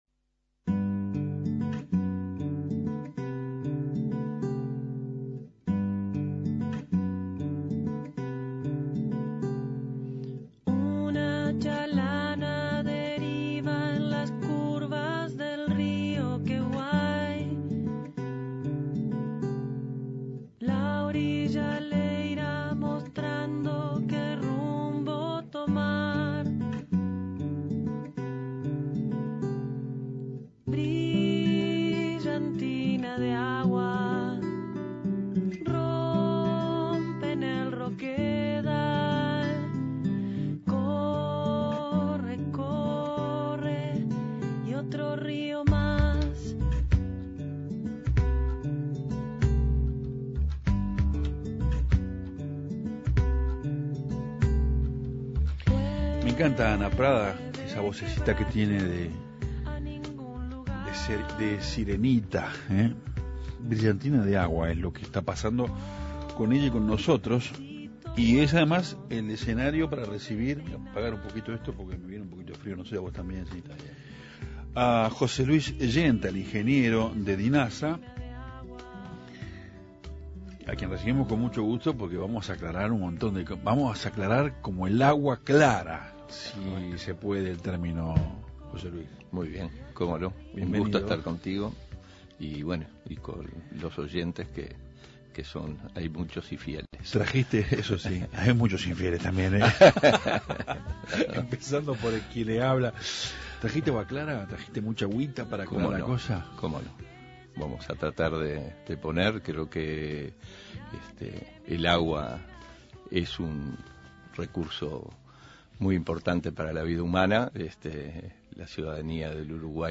El ingeniero José Luis Genta, director de la Dirección Nacional de Aguas y Saneamiento, dialogó sobre lo que significa este recurso natural, en el marco de la celebración de este Día, que fue el pasado lunes 22 de marzo.